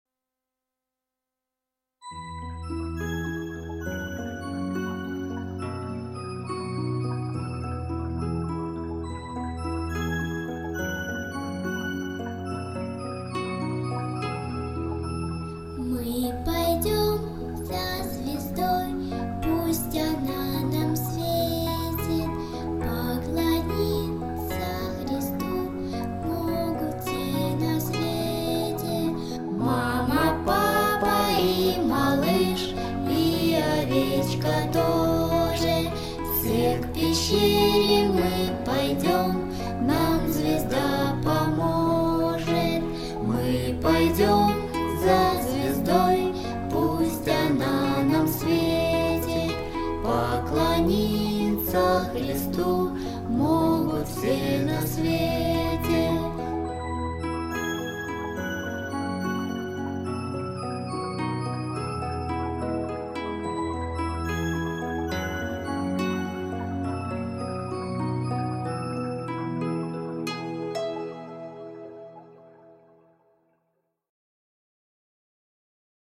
Детские песни / Христианские Песни ⛪